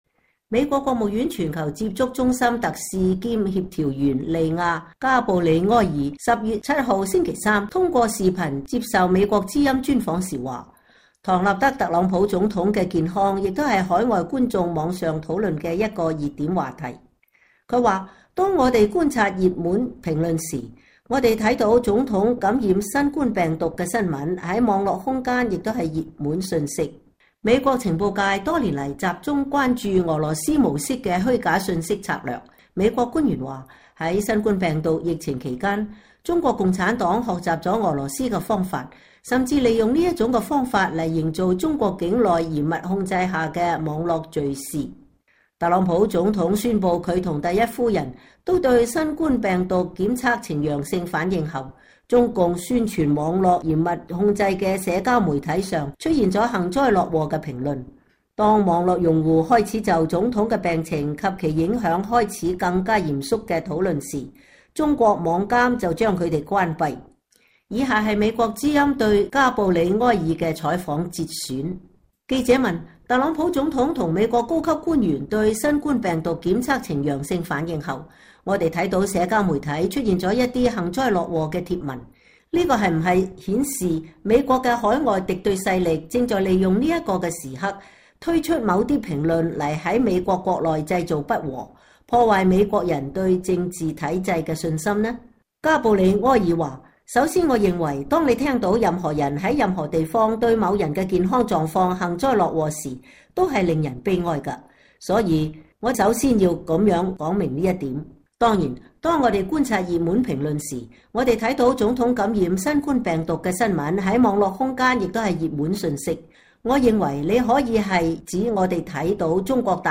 專訪美國務院官員- 外國對手散佈有關疫情和選舉的虛假信息
美國國務院全球接觸中心特使兼協調員利亞·加布里埃爾（Lea Gabrielle）星期三(10月7日)通過Skype視頻接受美國之音專訪時說，唐納德·特朗普總統的健康也是海外觀眾網上討論的一個熱點話題。